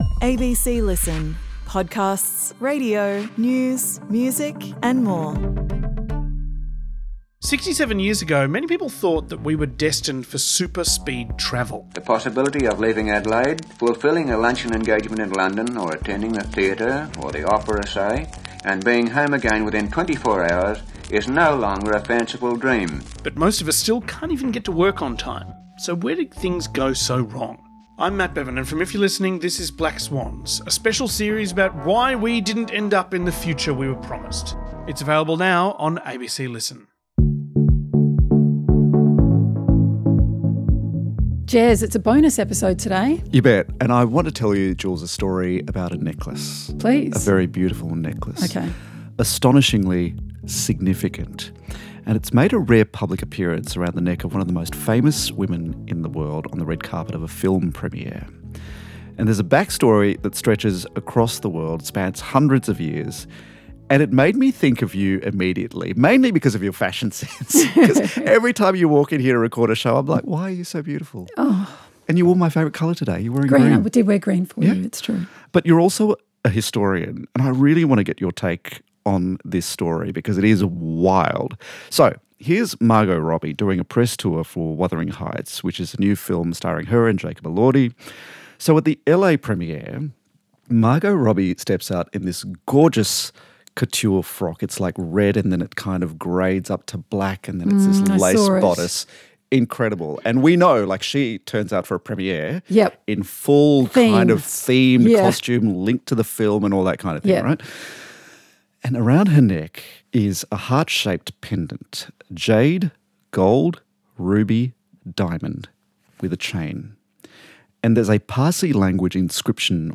Julia Baird and Jeremy Fernandez chat about the stories you're obsessed with, the stuff you've missed and the things that matter.